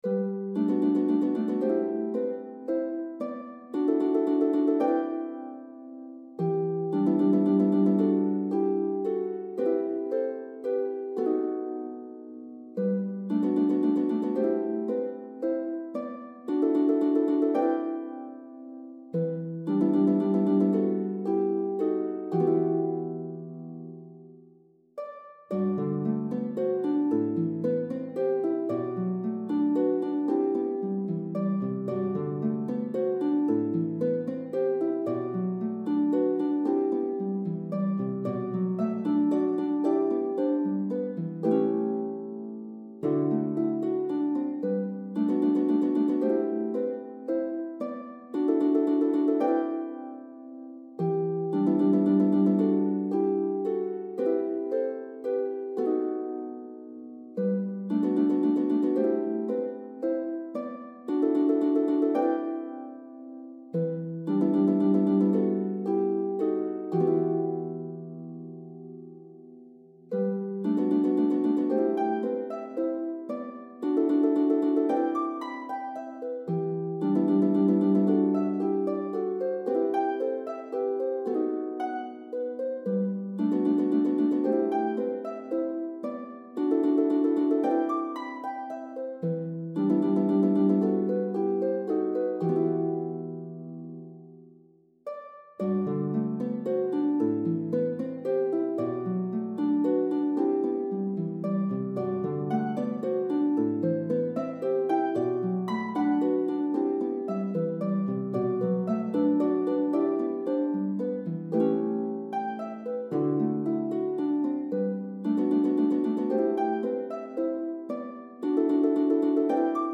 French hymn
solo pedal harp